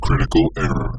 computer critical-error error robot space spaceship windows sound effect free sound royalty free Nature